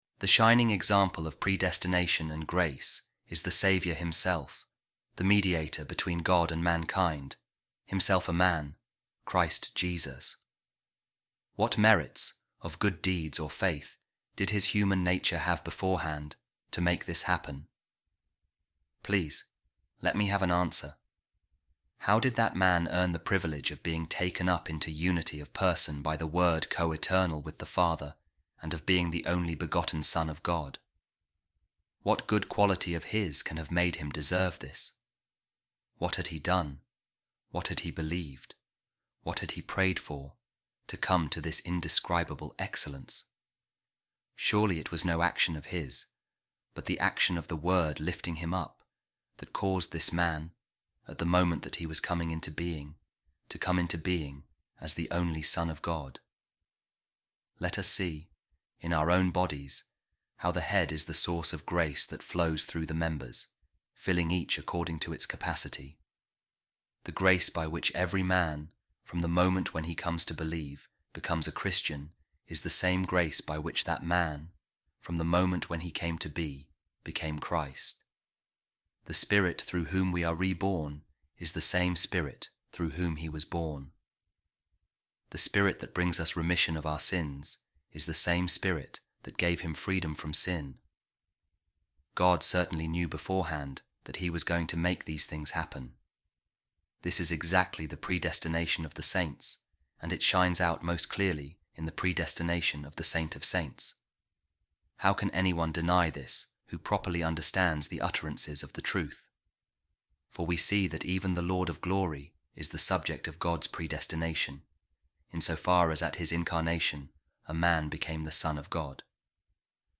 A Reading From The Book Of Saint Augustine, The Predestination Of The Saints | Jesus Christ, Son Of David According To The Flesh